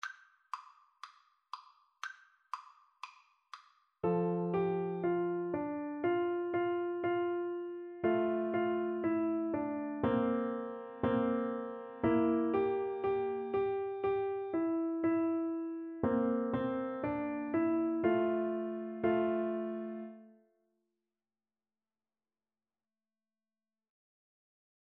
4/4 (View more 4/4 Music)
F major (Sounding Pitch) (View more F major Music for Piano Duet )
Molto Allegro (View more music marked Allegro)